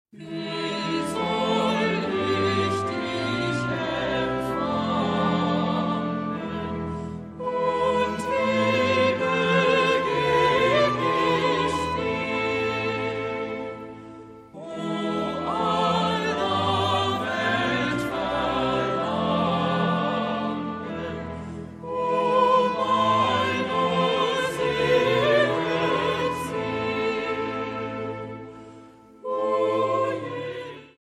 Festliche Advents- und Weihnachtsmusik
Vocal- und Orgelmusik Neuerscheinung 2010